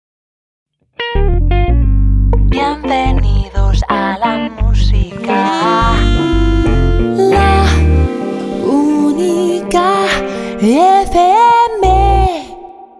THEME ID 1